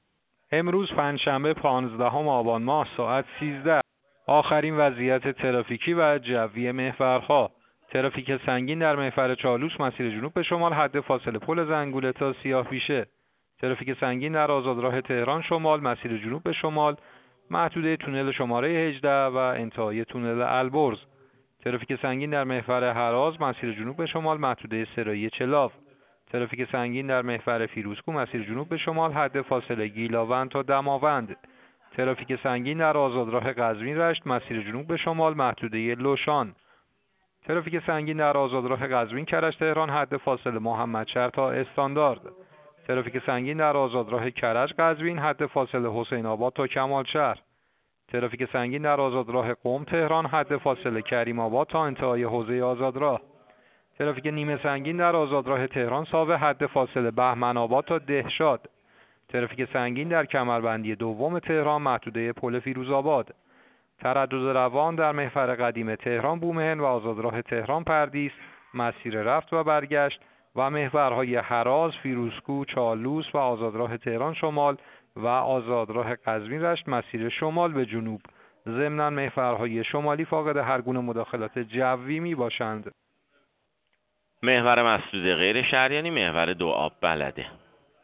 گزارش رادیو اینترنتی از آخرین وضعیت ترافیکی جاده‌ها ساعت ۱۳ پانزدهم آبان؛